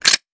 weapon_foley_pickup_20.wav